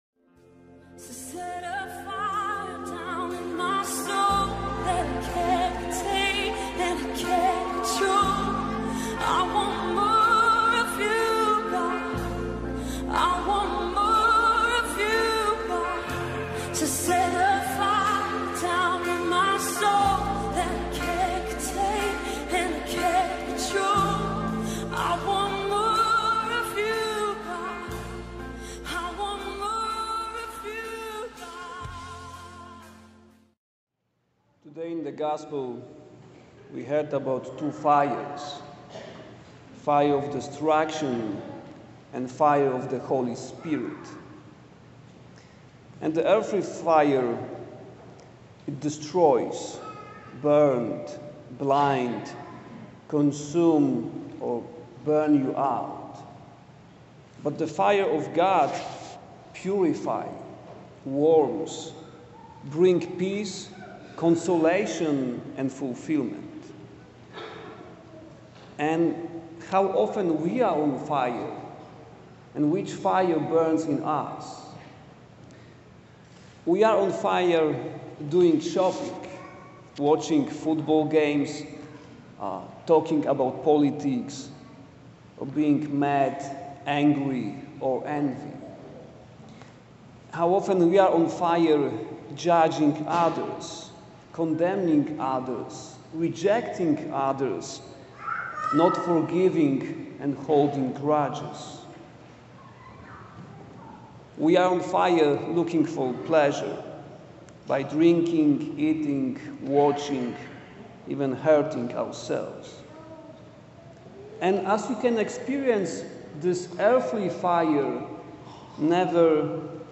homily-for-the-2-sunday-of-advent.mp3